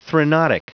Prononciation du mot threnodic en anglais (fichier audio)
threnodic.wav